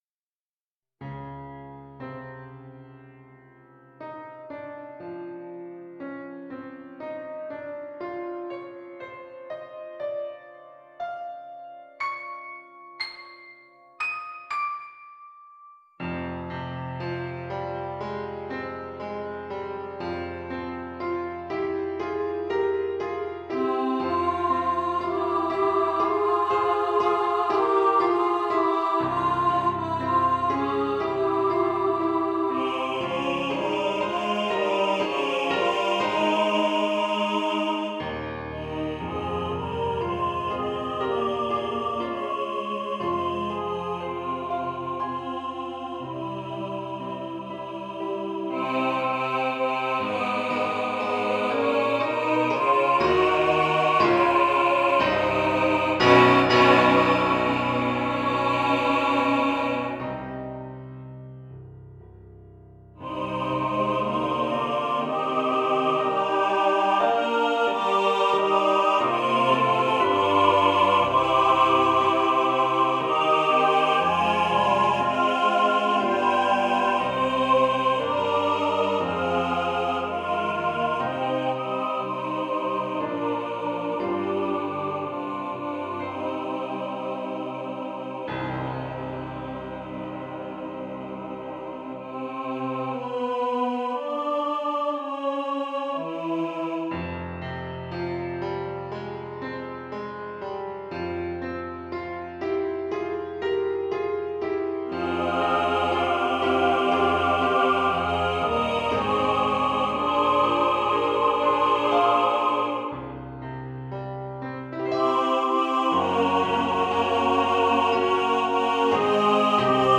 Alone - Midi Render - Click this box